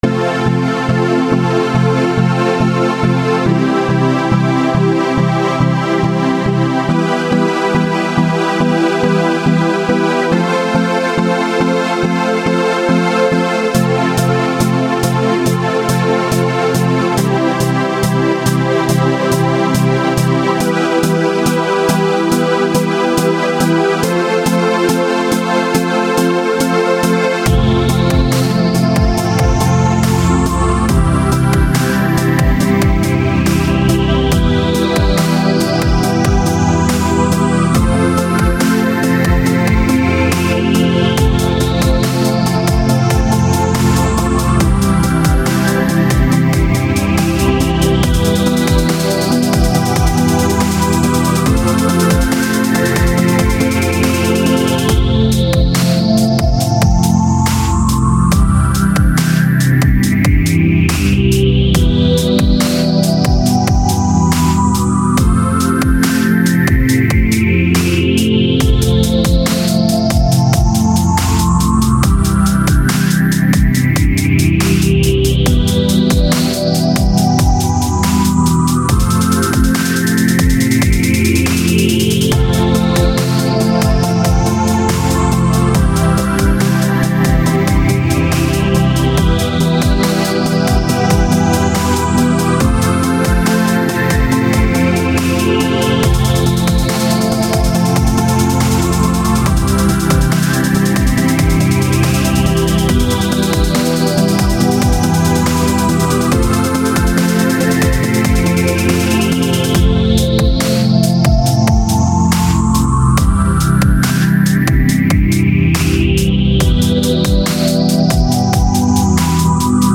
0 Thumb Up 미디어 듣기 두번째 샘플링곡 올리려고했는데 자꾸 오류뜨네요 ㅡㅡ 그래서 세번째로 올리려고 했던거 바로올려염 이건 순수창작물이구요 ㅋㅋㅋㅋㅋㅋㅋㅋㅋㅋㅋ 열정에 관한 랩에 잘어울릴 비트입니당 0 Scrap This!